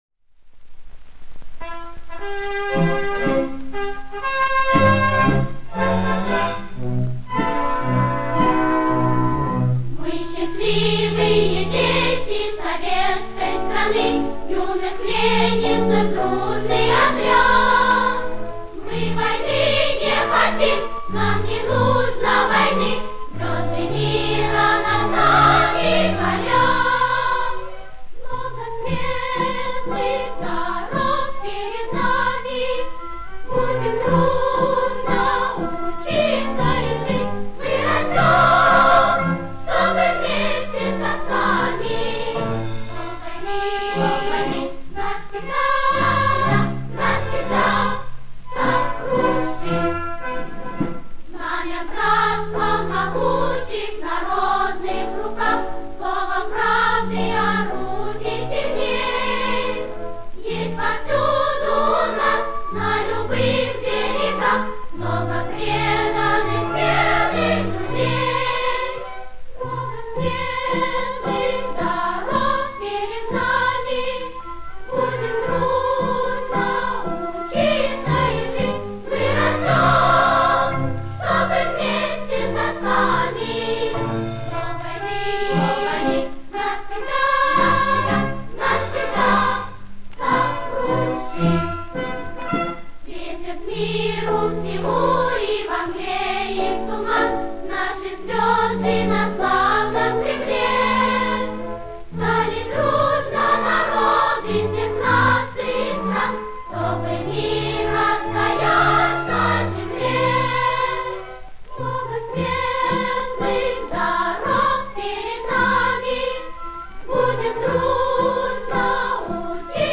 Источник грампластинка